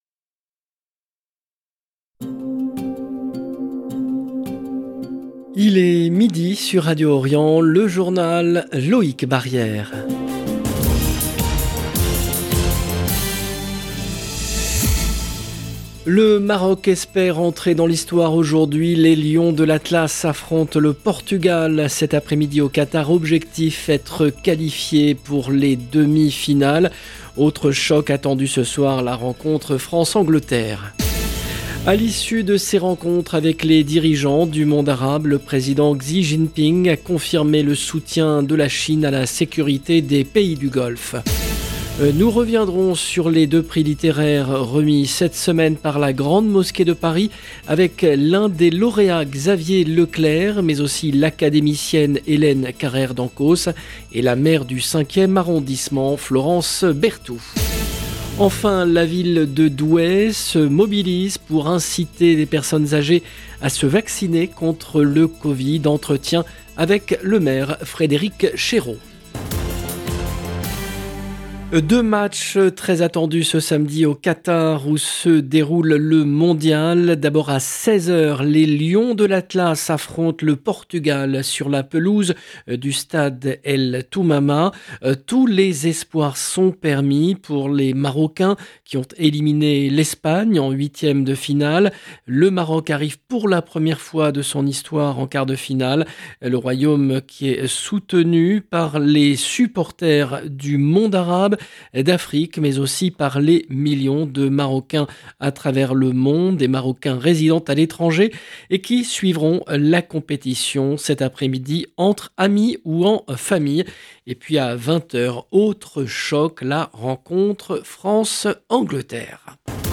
LE JOURNAL EN LANGUE FRANCAISE DE MIDI DU 10/12/22
Enfin la ville de Douai se mobilise pour inciter les personnes âgées à se vacciner contre le covid. Entretien avec le maire, Frédéric Chéreau. 0:00 12 min 56 sec